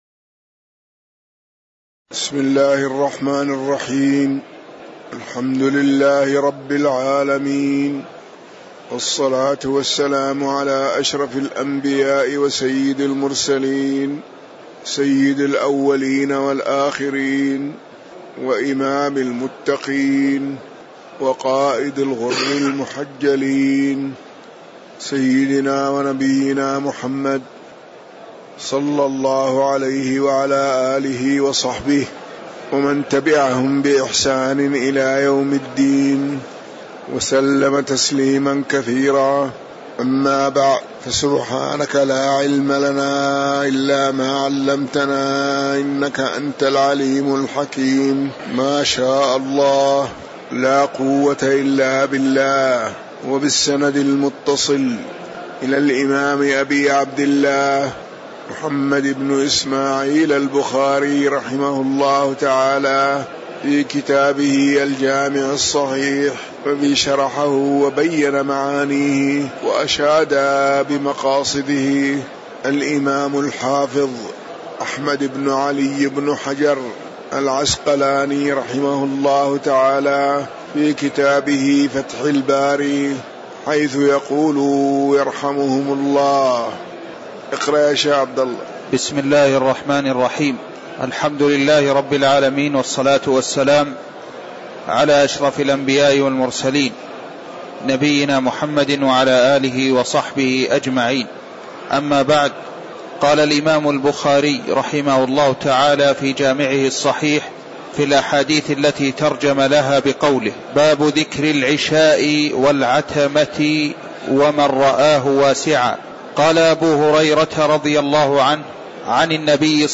تاريخ النشر ١٤ ربيع الأول ١٤٤١ هـ المكان: المسجد النبوي الشيخ